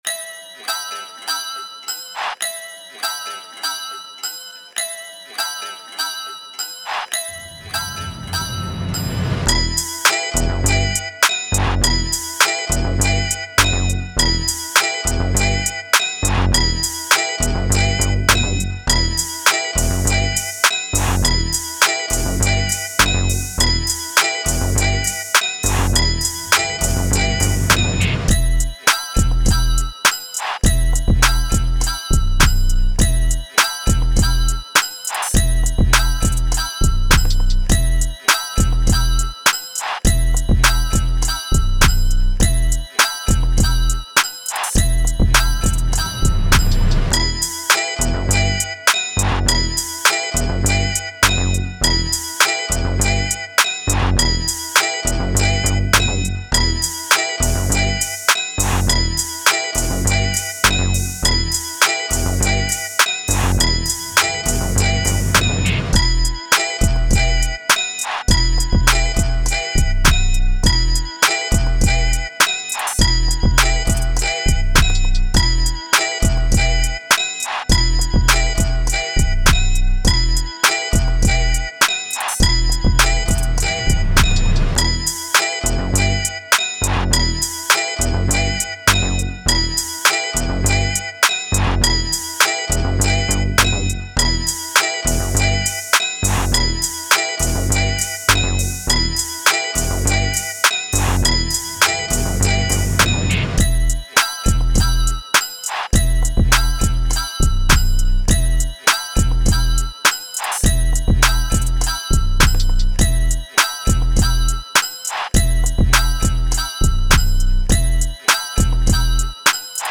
Trap, West Coast, Hip Hop
Amin